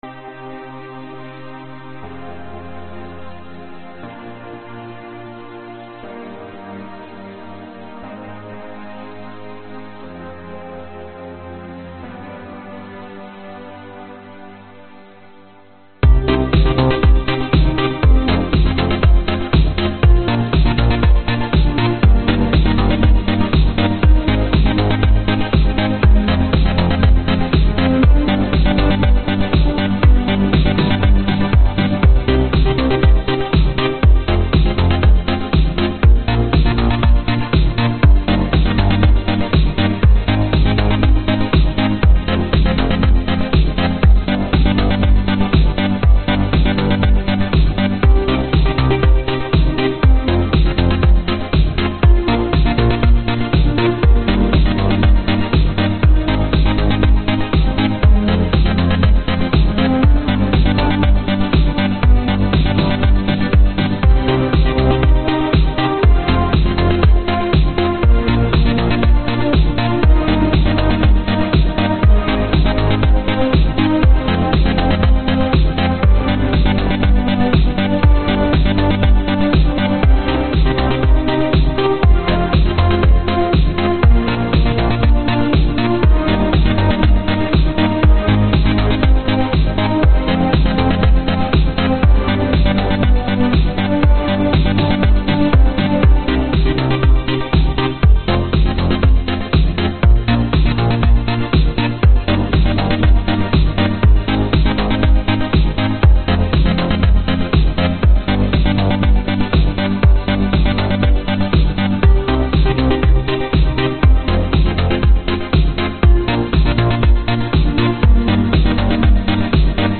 描述：回到80年代。
Tag: 贝斯 合成器 吉他